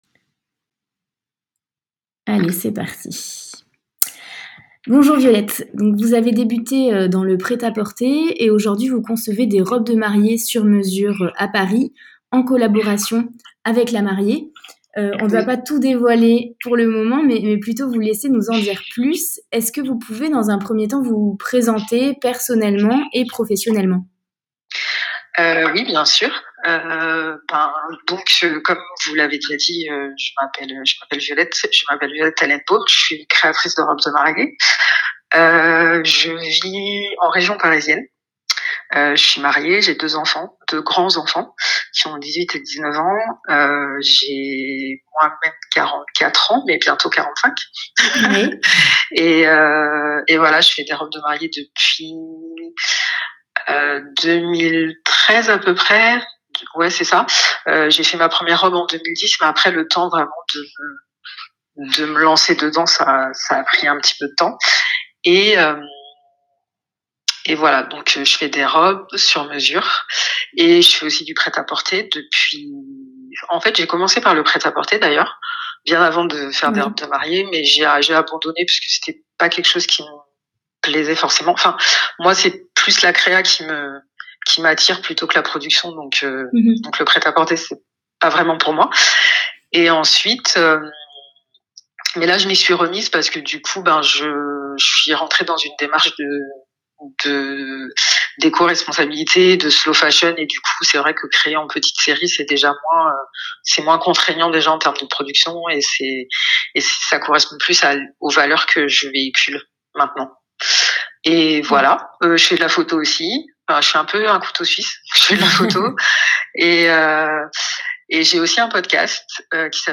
Pouvez-vous vous présenter ?